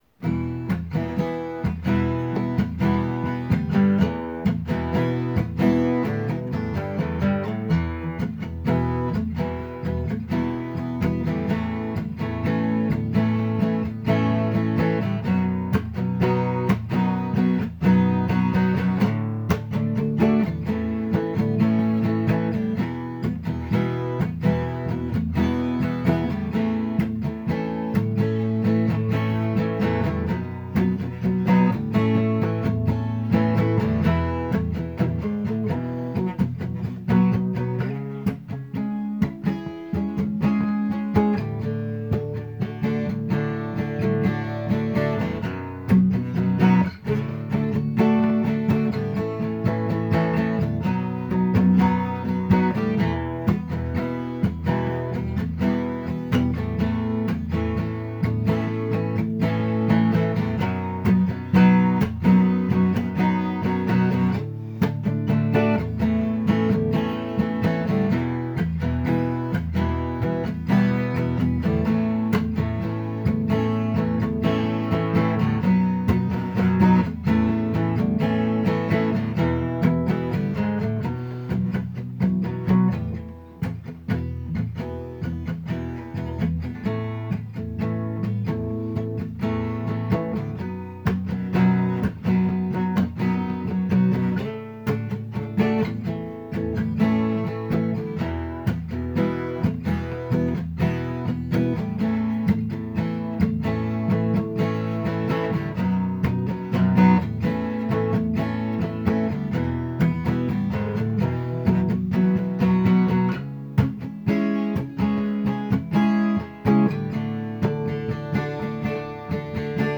music_smorgasbord_seriouscontender_acoustic.m4a